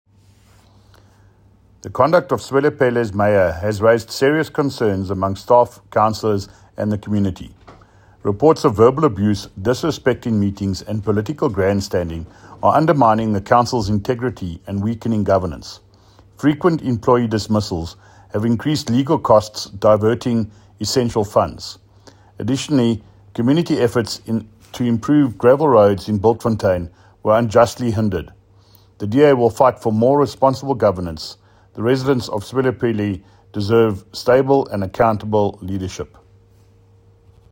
English soundbite by David Mc Kay MPL,